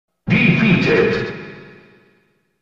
Smash Melee – Defeated sound button getting viral on social media and the internet Here is the free Sound effect for Smash Melee – Defeated that you can download and make hilarious memes, and edit YouTube videos as well as Instagram reels, funny memes, and vines.